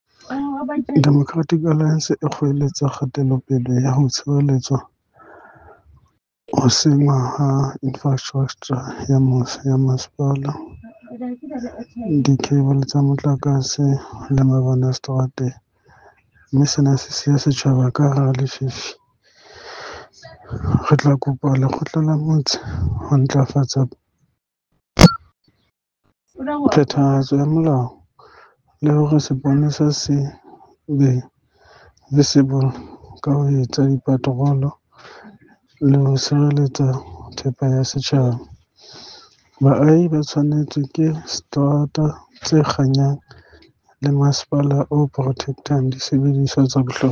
Sesotho soundbites by Cllr Sepatala Chabalala.